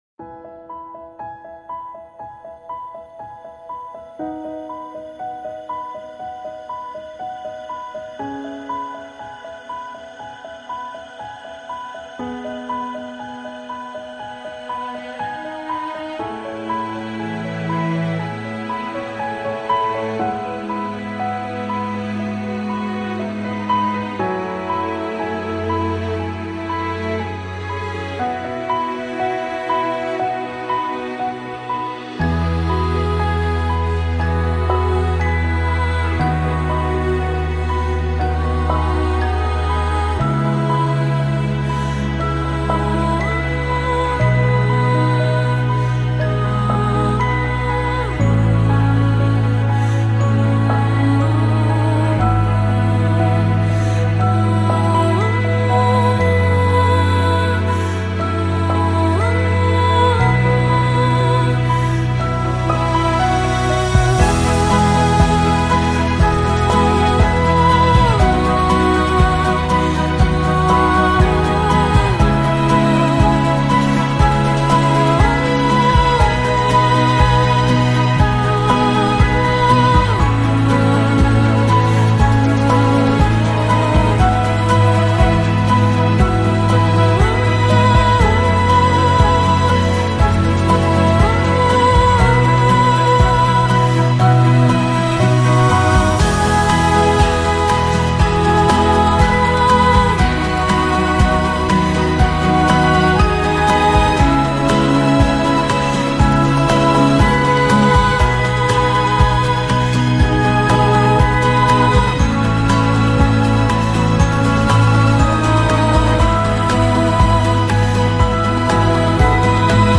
音频：新加坡心灵法门共修会十周年分享会花絮！【更新】